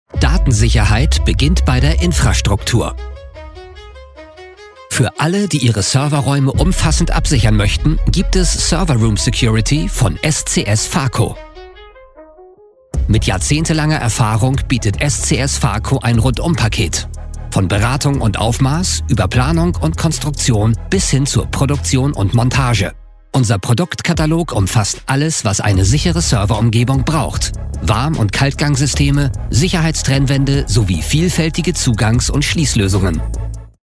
Commerciale, Cool, Mature, Amicale, Corporative
Vidéo explicative